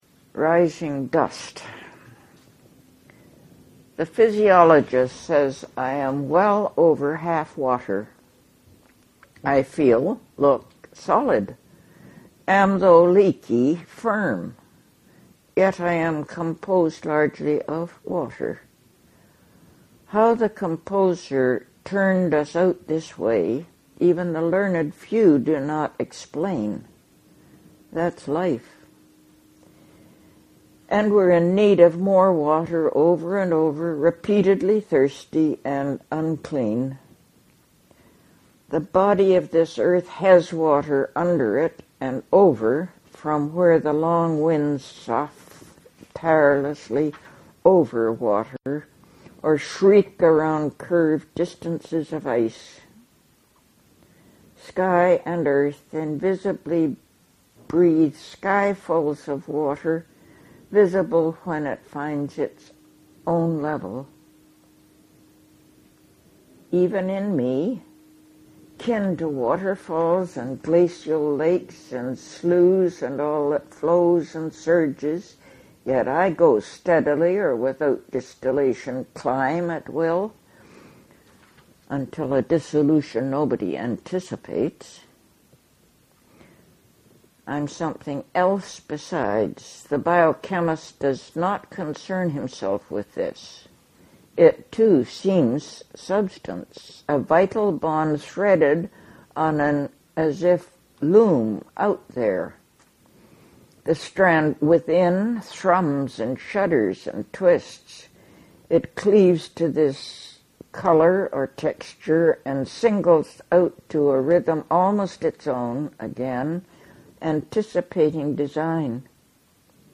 Margaret Avison reads Rising Dust from Concrete and Wild Carrot